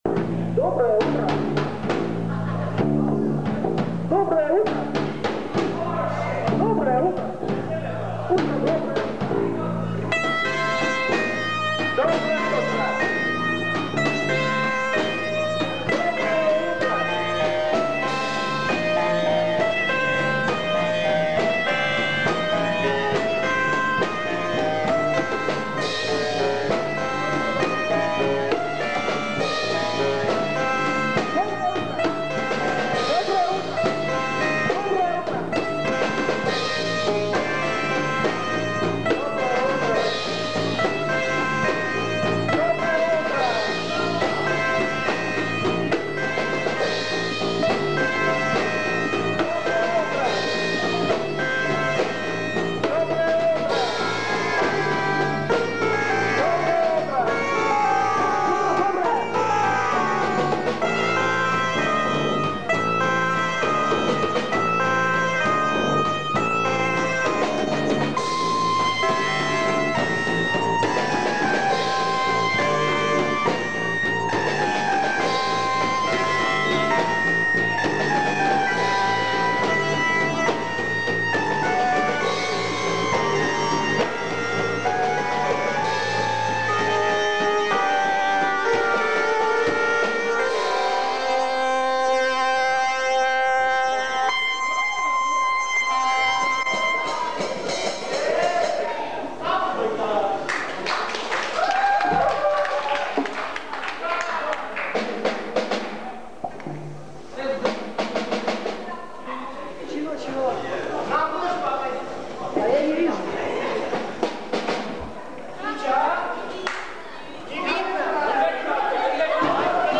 КОНЦЕРТ В КИНОТЕАТРЕ "ПИОНЕР"
синтезатор
барабанная установка